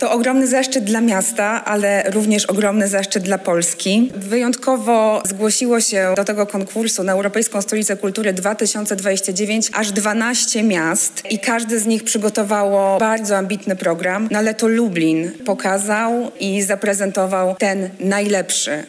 „Hasłem przewodnim programu jest RE:UNION. To hasło dwołuje się do spotkania, dialogu, wzajemnej troski i nowego spojrzenia na miasto.” – podkreśla Marta Cienkowska, Minister Kultury i Dziedzictwa Narodowego: